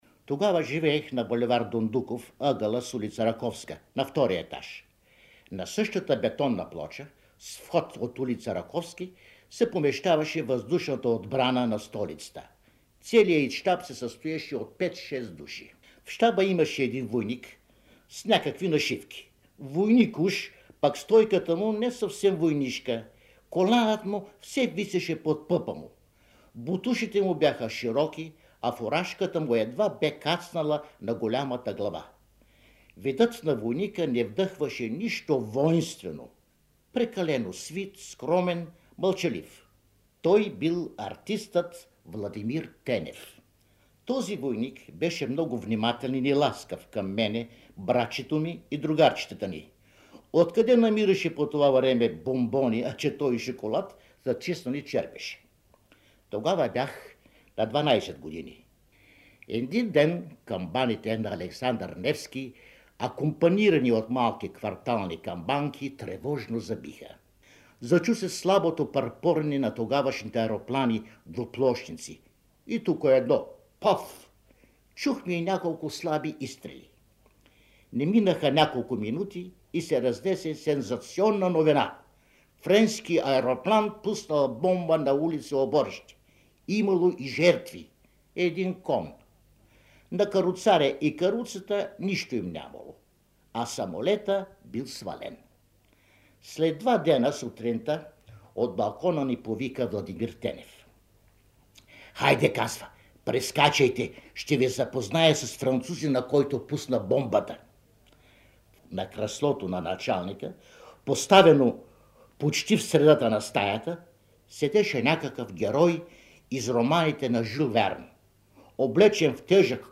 През 1976 година в предаването „Театрален алманах“